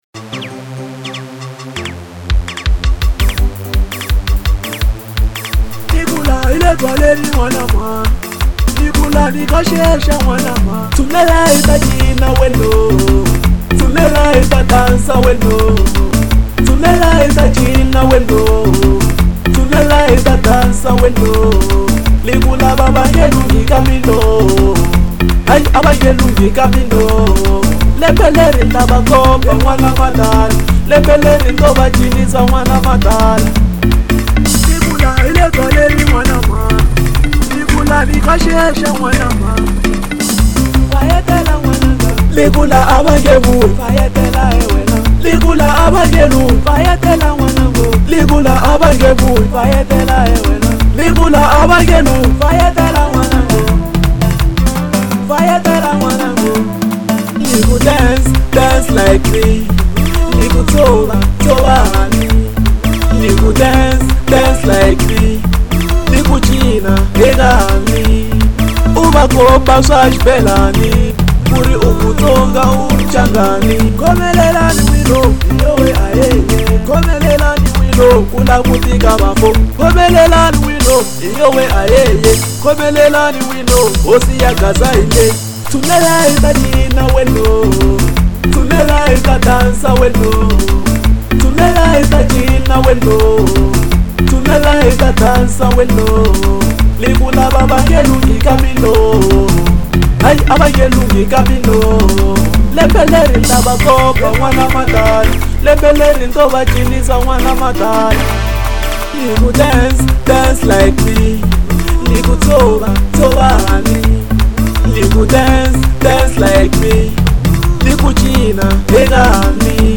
03:50 Genre : Xitsonga Size